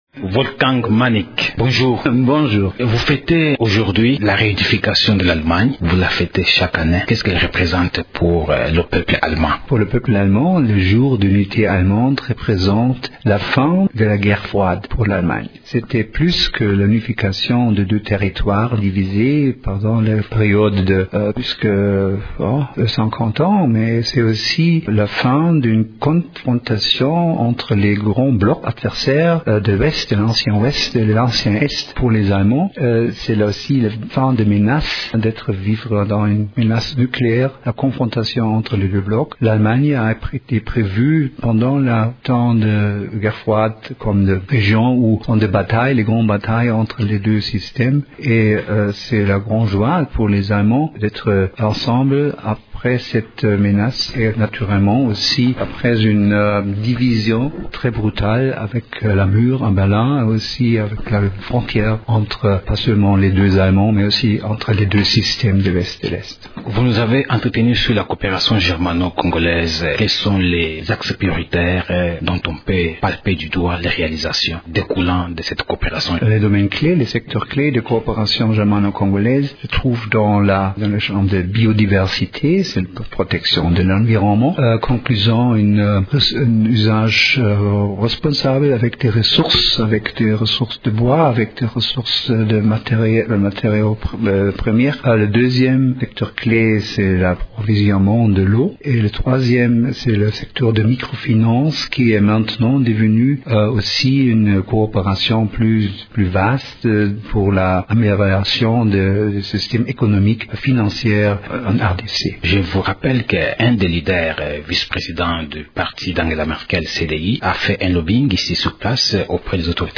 Invité de Radio Okapi ce matin, l’ambassadeur d’Allemagne en RDC, Wolfgang Manig, a indiqué que cette réunification représente la fin de la guerre froide pour son pays, autrefois divisé entre les blocs Est-Ouest.